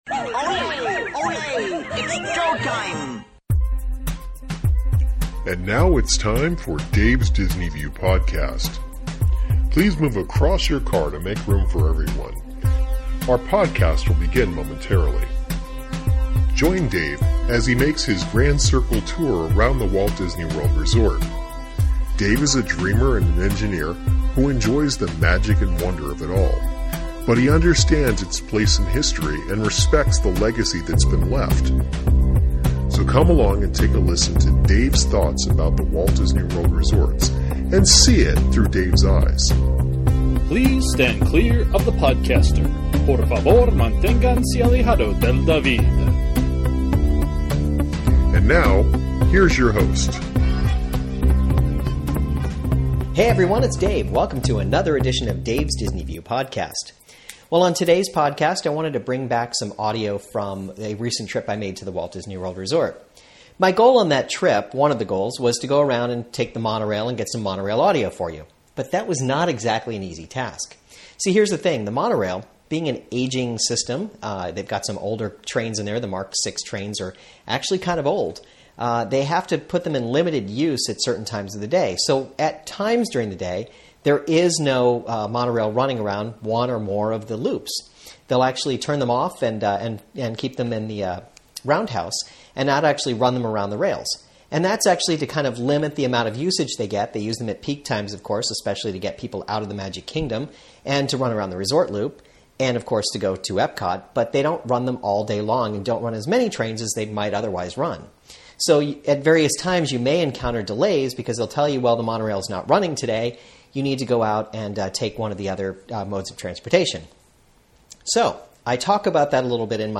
I talk about the monorail being unavailable during off-peak times, and ride the ferry boat to the magic kingdom. Plus, I tell a few stories as I wander around.